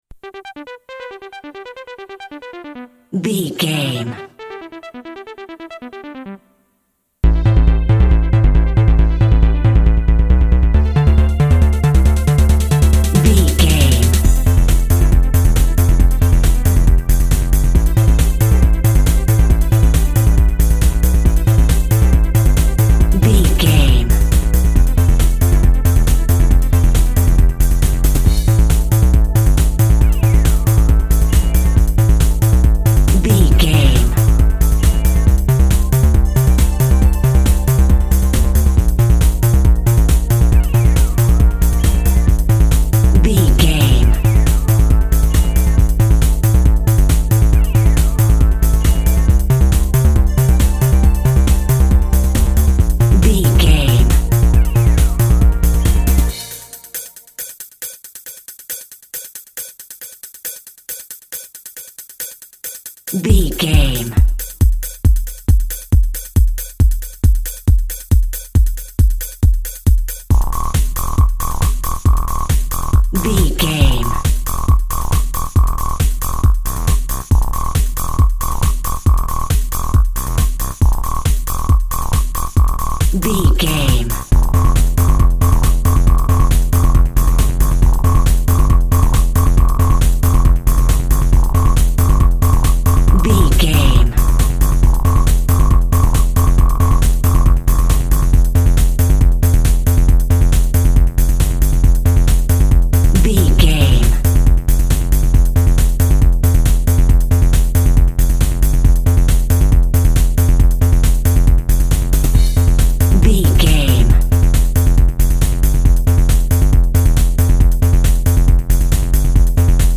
Electronic Music Deep Down.
Aeolian/Minor
groovy
futuristic
uplifting
techno
trance
synth lead
synth bass
electronic drums
Synth Pads